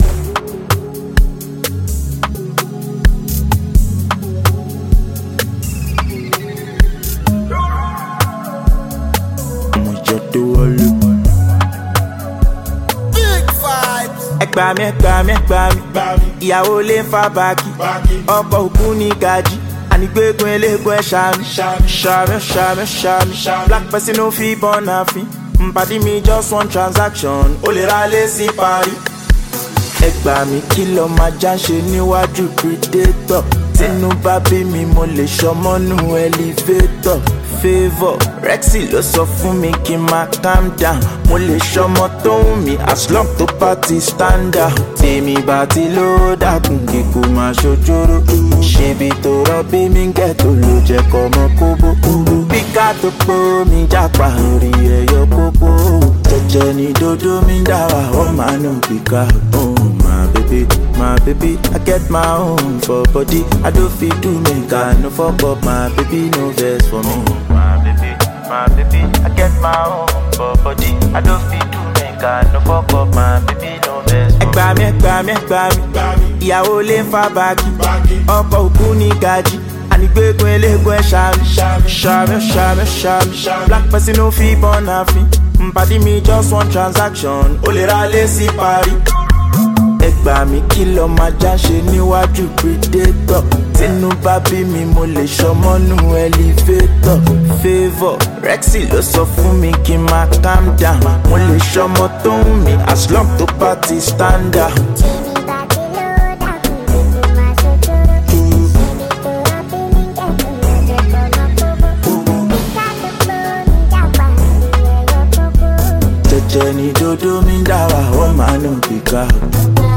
Afrobeats